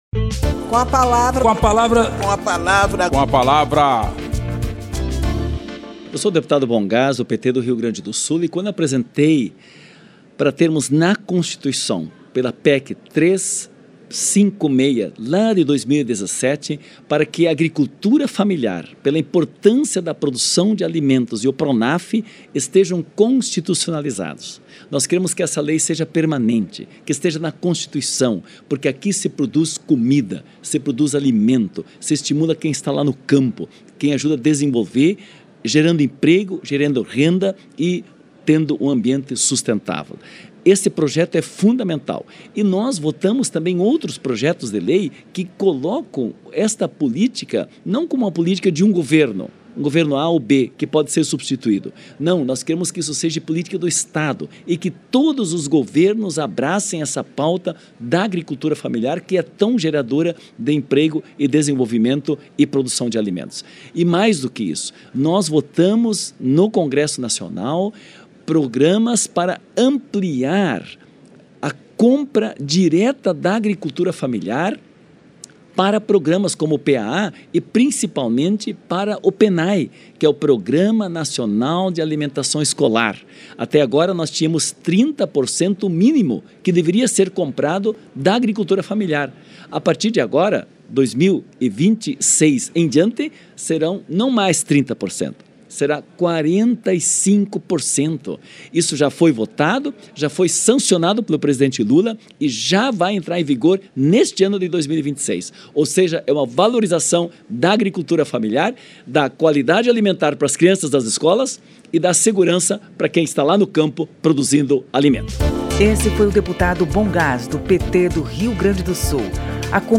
O deputado Bohn Gass (PT-RS) ressalta a importância do fortalecimento da agricultura familiar, por meio de políticas públicas de Estado abraçadas por todos os agentes públicos. Na avaliação do parlamentar, ela é o pilar do desenvolvimento rural no país, que responde por cerca de 67% dos empregos no campo.
Espaço aberto para que cada parlamentar apresente aos ouvintes suas propostas legislativas